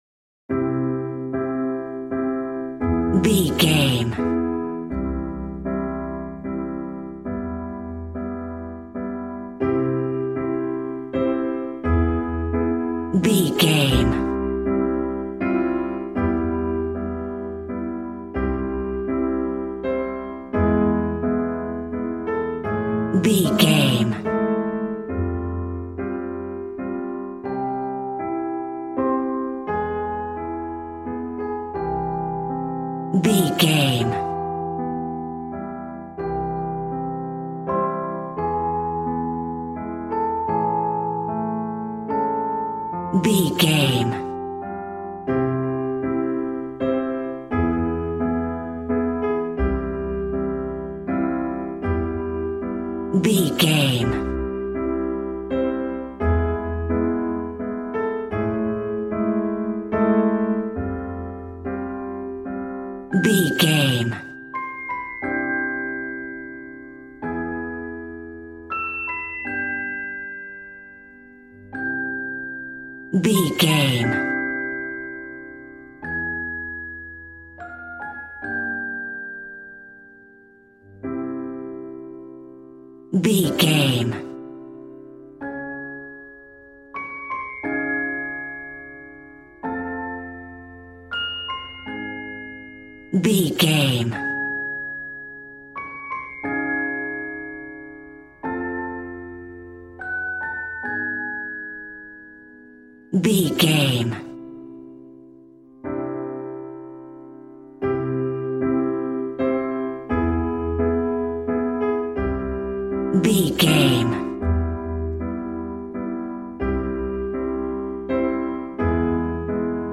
Regal and romantic, a classy piece of classical music.
Aeolian/Minor
WHAT’S THE TEMPO OF THE CLIP?
regal
strings
violin